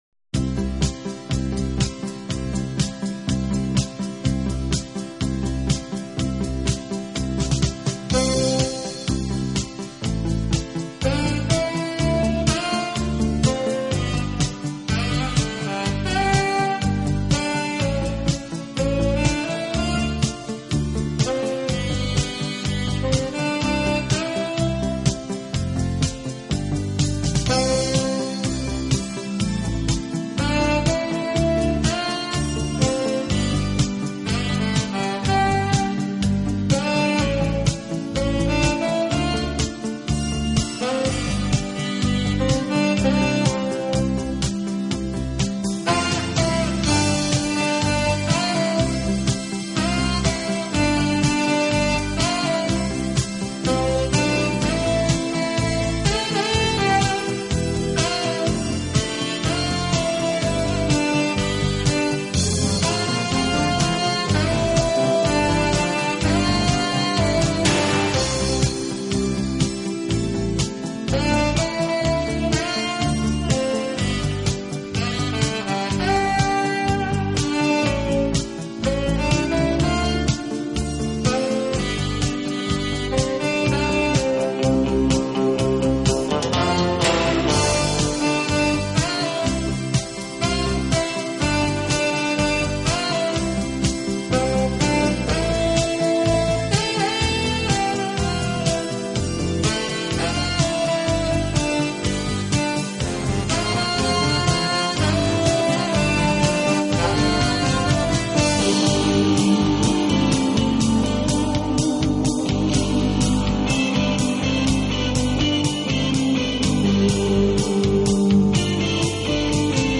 Instrumental | MP3 | VBR 320 kbit
巨大力量，总的来说，它的柔和优美的音色，具有弦乐器的歌唱风格。